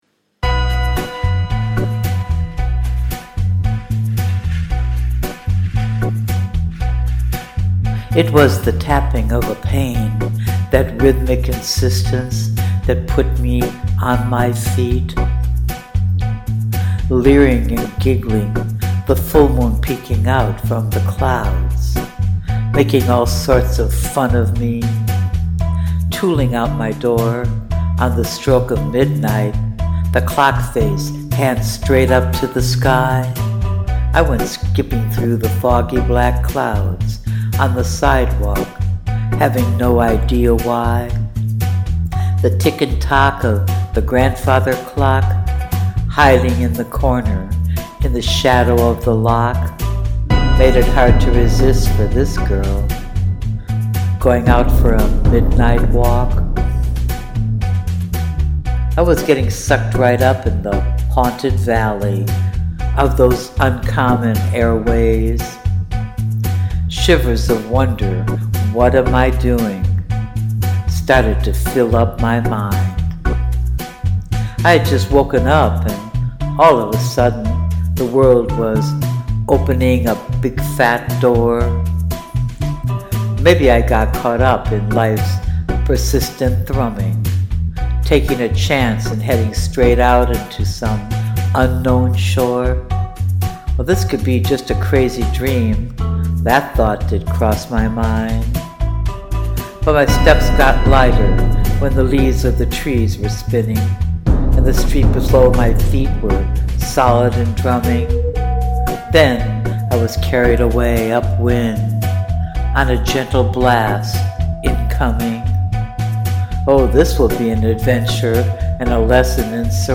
And the perfect BOOMs of the soundtrack…